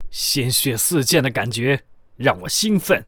文件 文件历史 文件用途 全域文件用途 Kg_tk_03.ogg （Ogg Vorbis声音文件，长度3.1秒，107 kbps，文件大小：40 KB） 源地址:地下城与勇士游戏语音 文件历史 点击某个日期/时间查看对应时刻的文件。 日期/时间 缩略图 大小 用户 备注 当前 2018年5月13日 (日) 02:14 3.1秒 （40 KB） 地下城与勇士  （ 留言 | 贡献 ） 分类:卡坤 分类:地下城与勇士 源地址:地下城与勇士游戏语音 您不可以覆盖此文件。